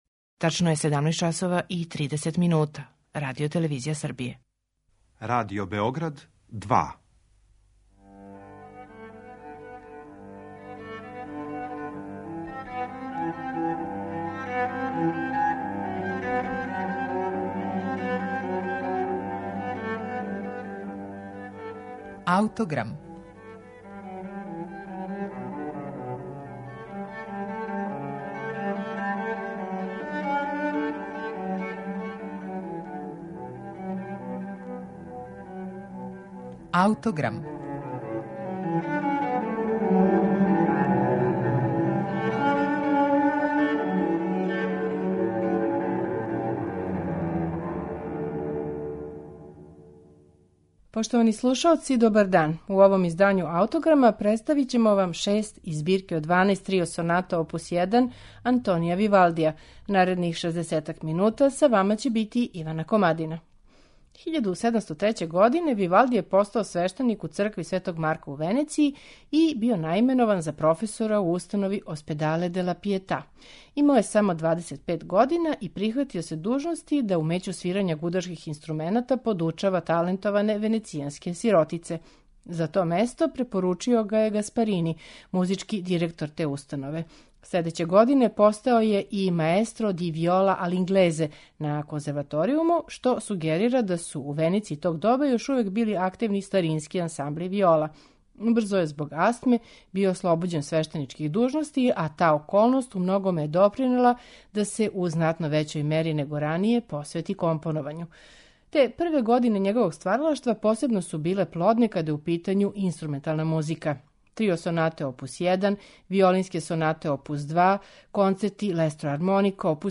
Трио соната је у доба барока, попут гудачког квартета један век касније, била основни вид камерног музицирања.
Чућете их у интерпретацији чланова ансамбла L'Arte Dell'Arco, под управом Кристофера Хогвуда, оствареној на инструментима из венецијанске установе Ospedale della Pietà, у којој је Вивалди радио као професор.